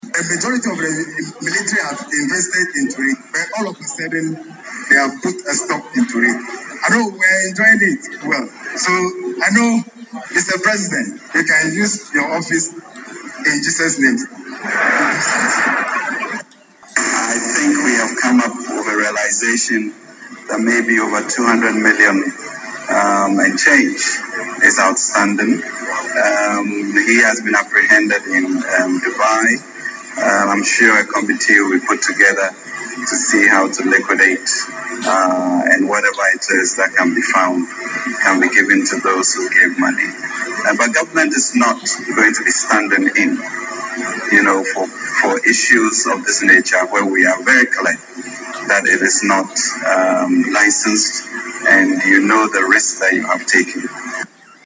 The Minister of Finance, Mr Ken Ofori-Atta during an interaction with officers of the Ghana Armed Forces (GAF) at the Burma Camp in Accra on Friday, February 22, assured the officers that the assets of Menzgold CEO, Nana Appiah Mensah will be liquidated to repay the deposits of the clients of the troubled company.
President Akufo-Addo who was with the Finance Minister rather asked him to respond to the soldiers’ appeal.